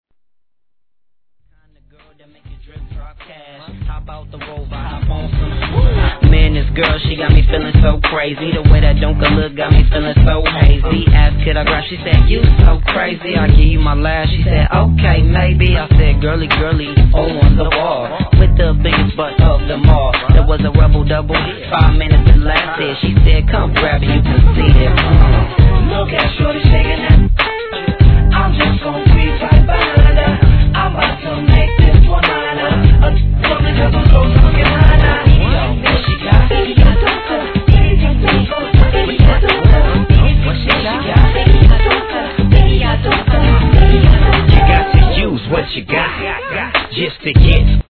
1. G-RAP/WEST COAST/SOUTH